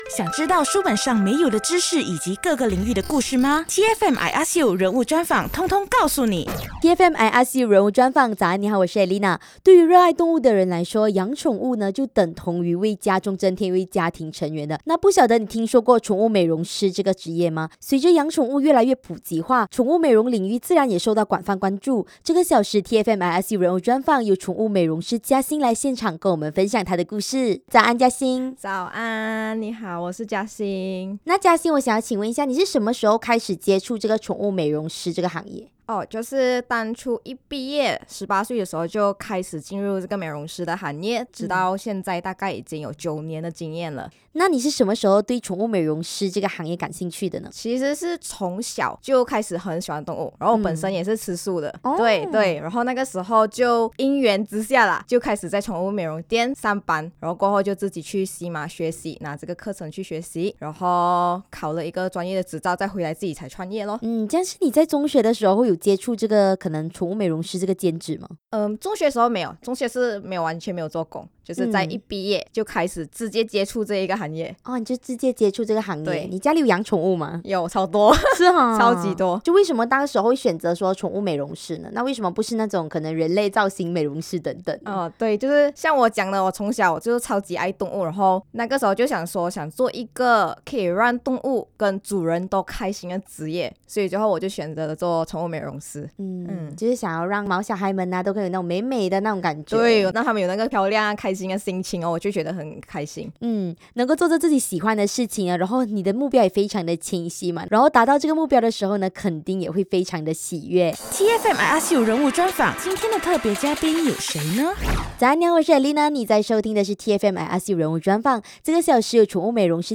人物专访 宠物美容师